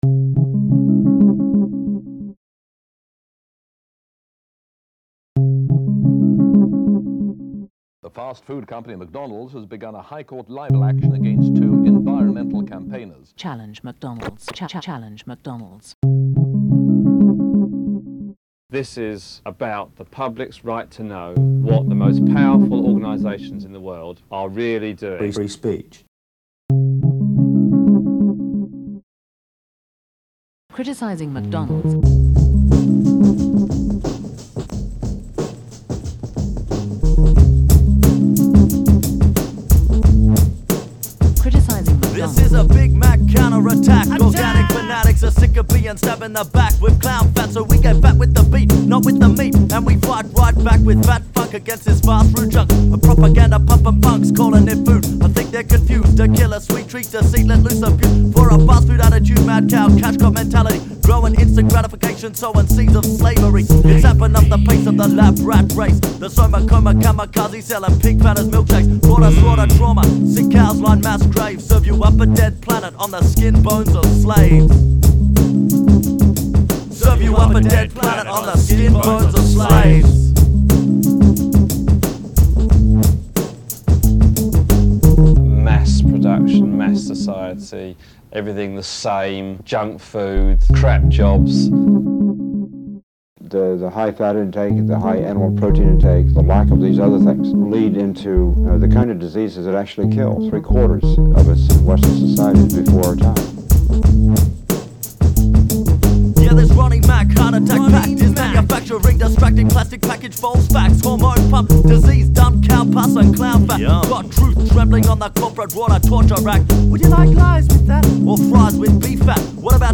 Rap song